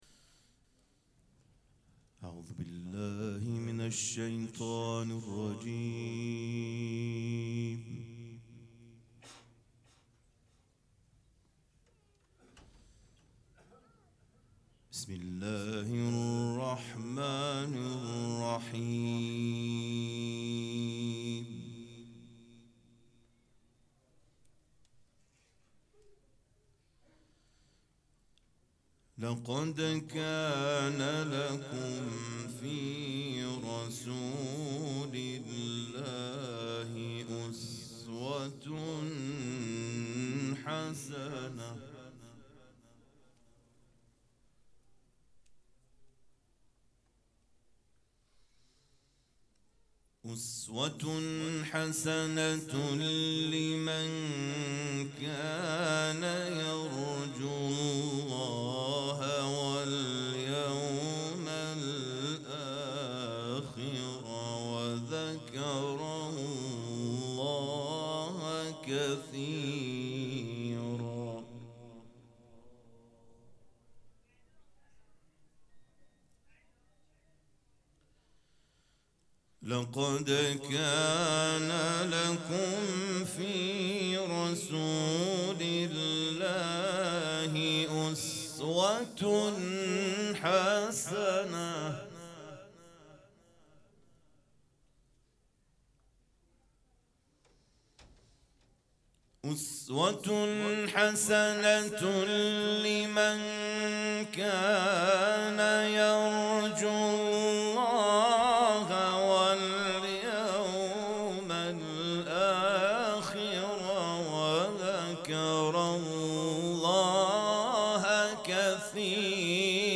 مراسم جشن ولادت حضرت زینب سلام‌الله‌علیها
قرائت قرآن